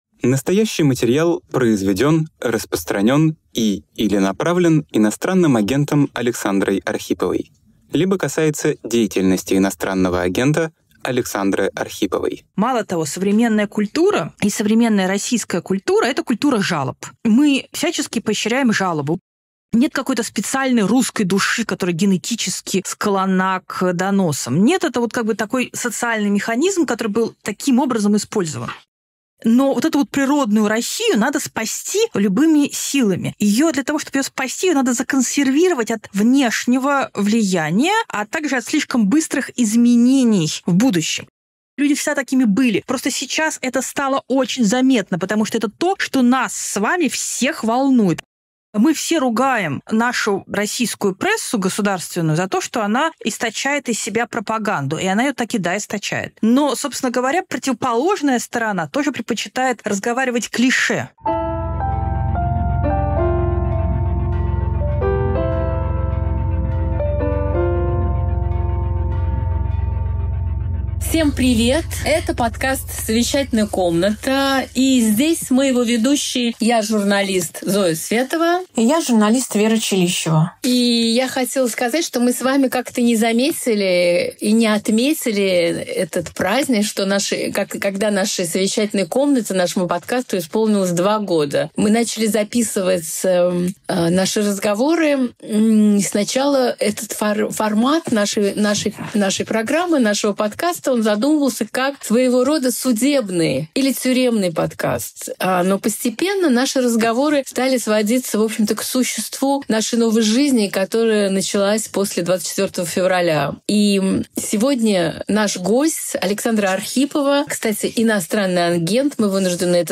Разговор о тяге к доносу, некроязе и шоке эмиграци